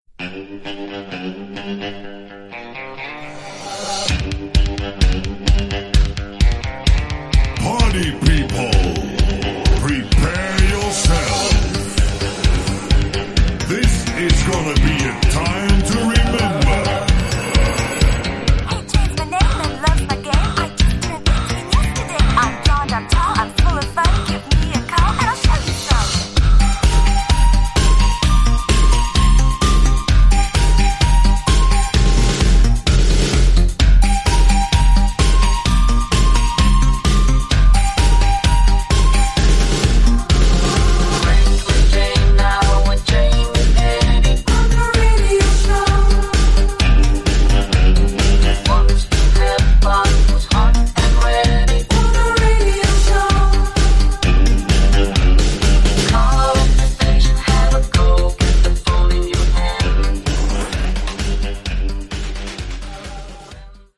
mixed & remixed by various DJs